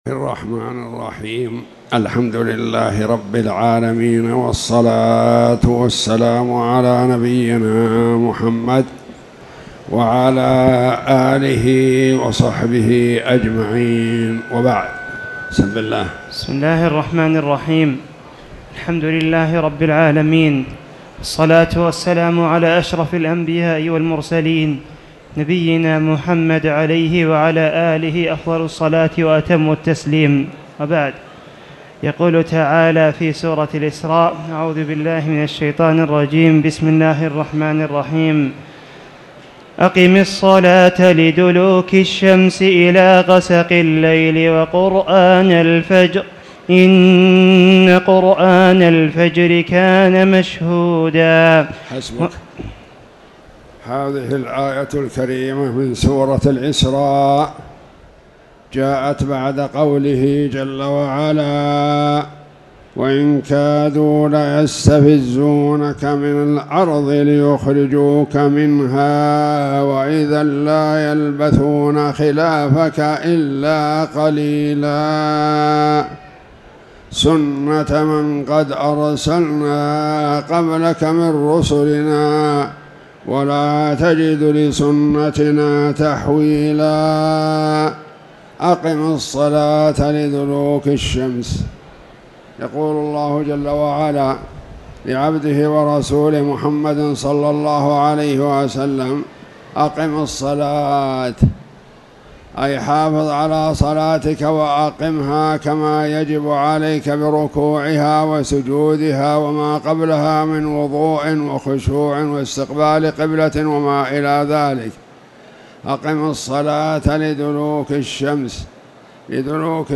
تاريخ النشر ١ ذو القعدة ١٤٣٧ هـ المكان: المسجد الحرام الشيخ